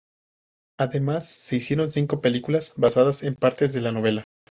Pronounced as (IPA) /baˈsadas/